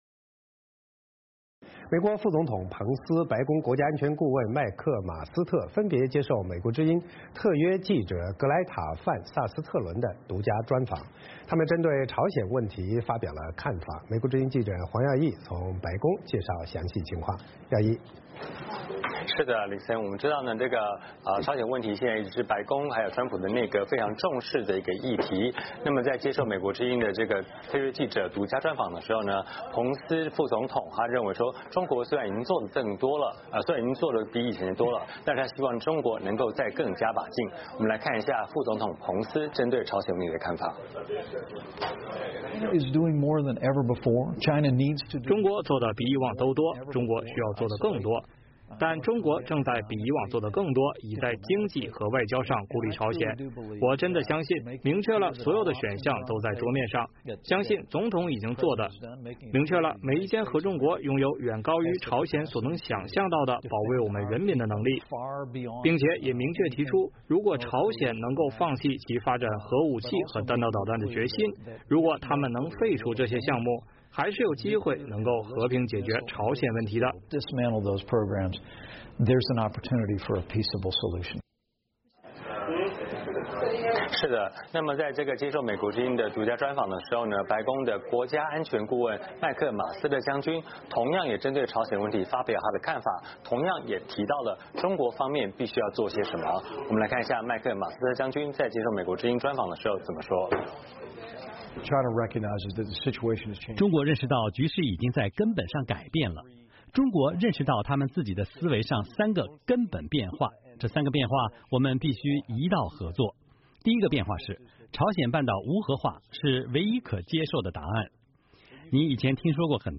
VOA连线